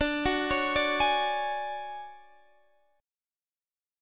DM#11 Chord
Listen to DM#11 strummed